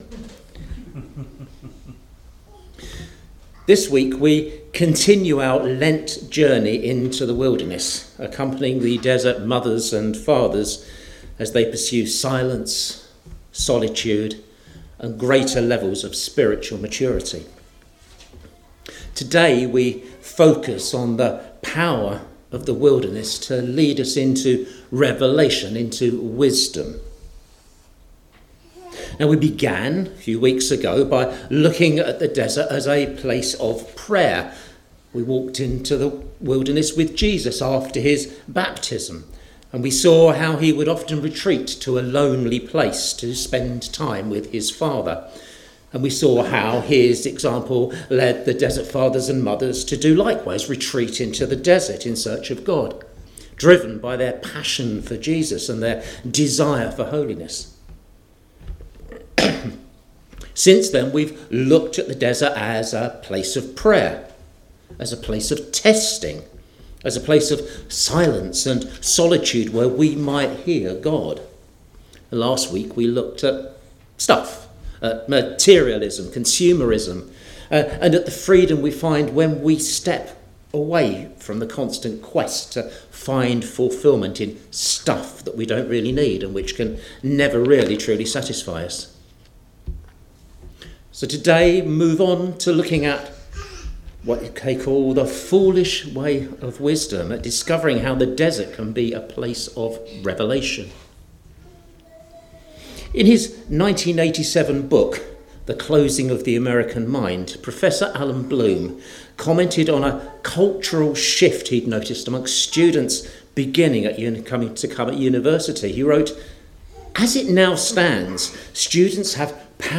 James 3:13–18 – 15th March 2026 – Tamworth Baptist Church